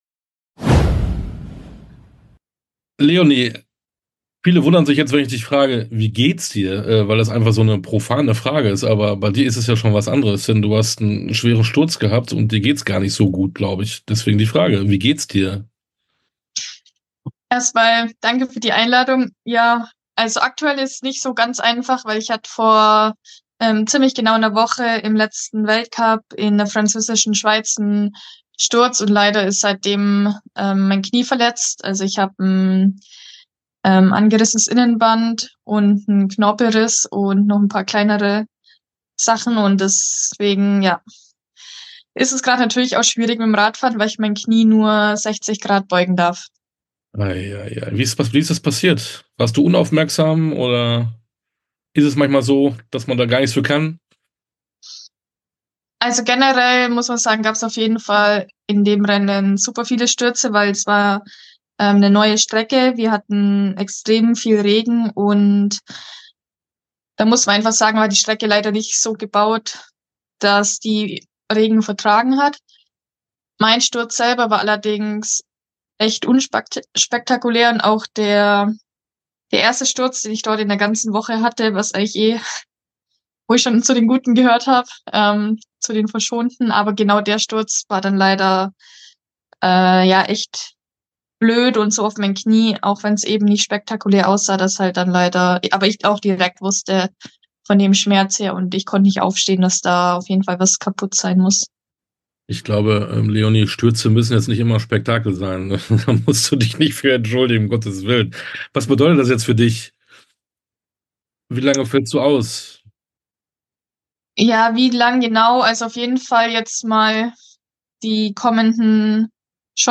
Interviews in voller Länge